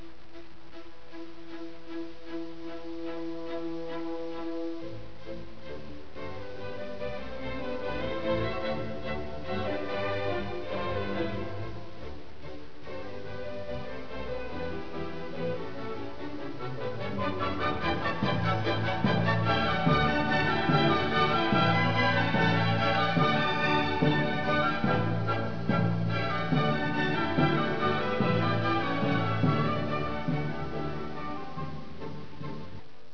Nozze di contadini: si avvicina ad un piccolo villaggio e giungono le note di un motivo folkloristico  eseguito durante una festa di nozze. I fagotti, i clarinetti e gli strumenti ad arco tentano di imitare il suono  campestre delle zampogne ed eseguono una ritmata e saltellante melodia.